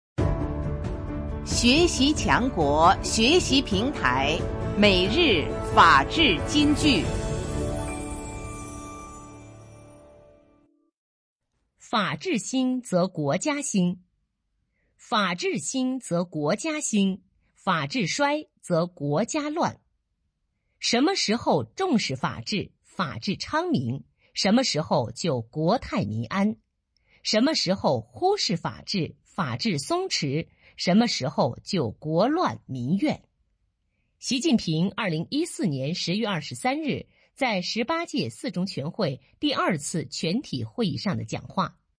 每日法治金句（朗读版）|法治兴则国家兴 _ 创建模范机关 _ 福建省民政厅